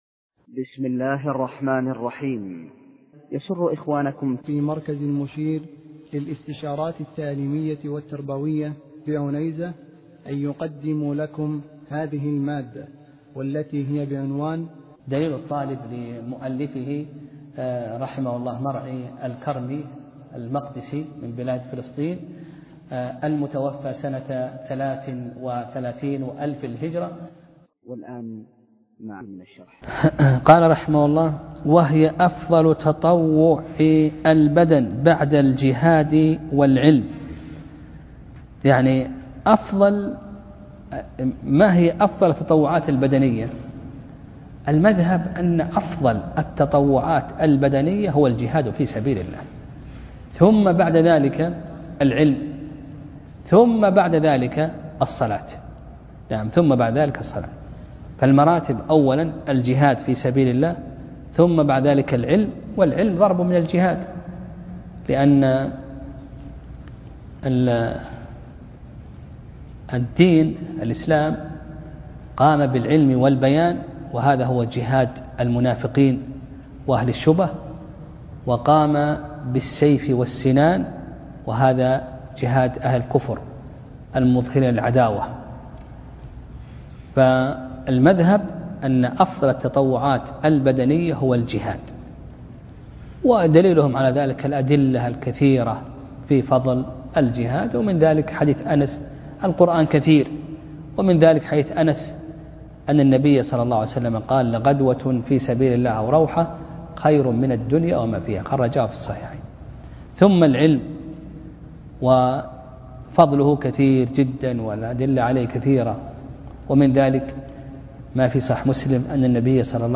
درس (3): باب صلاة التطوع